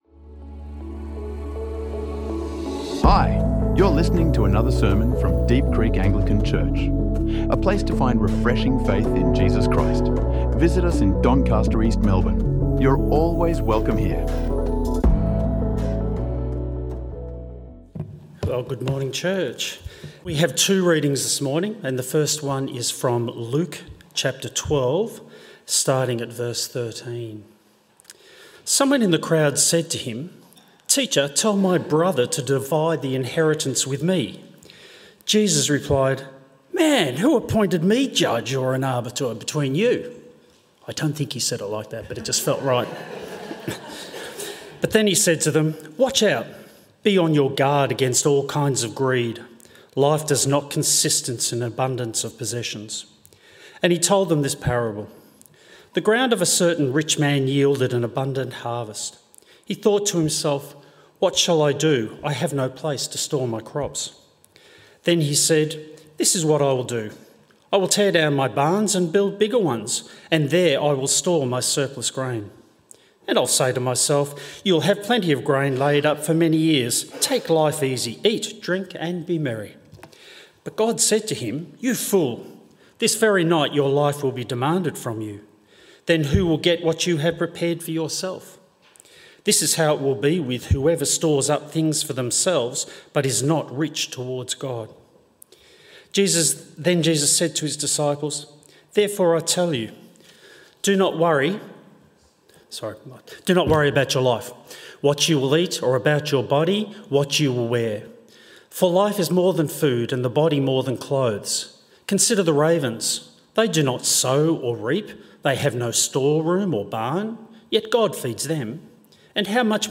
Giving and Generosity | Sermons | Deep Creek Anglican Church